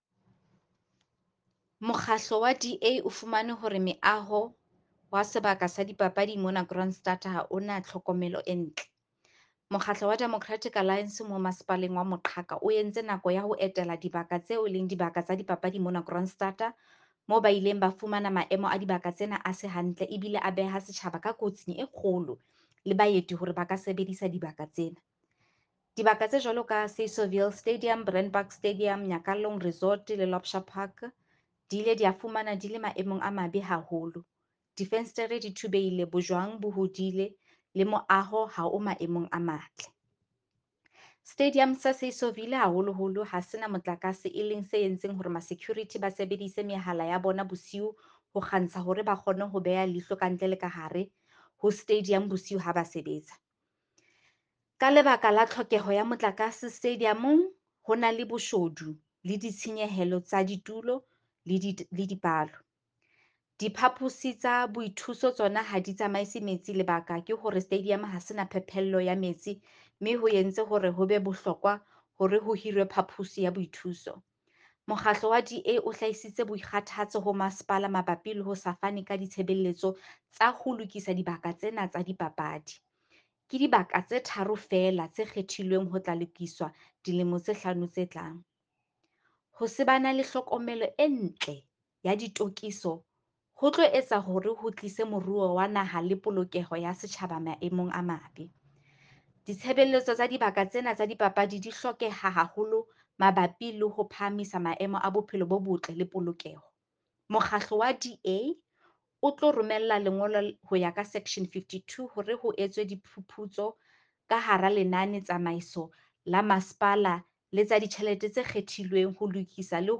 Sesotho soundbites by Cllr Mbali Mnaba.